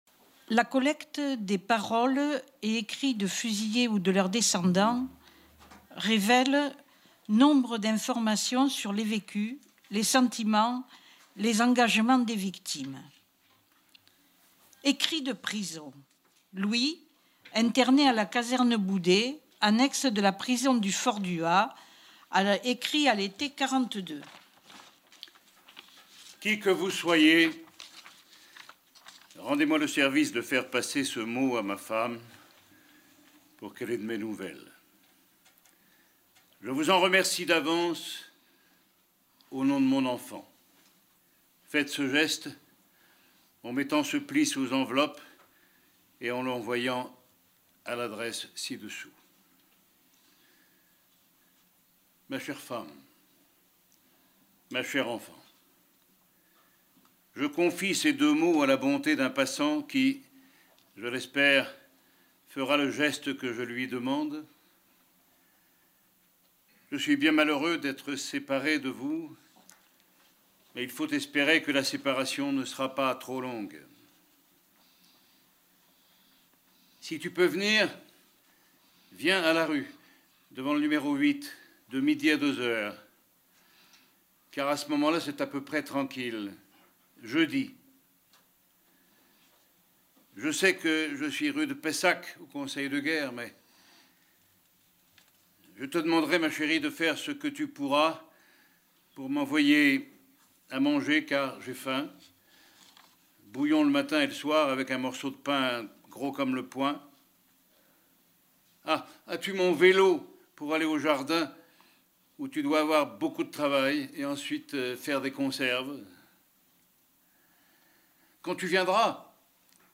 Lecture des textes par Pierre SANTINI :
Archives départementales de la Gironde
Lecture ponctuée par les chants interprétés par la Chorale des Amis de l’Ormée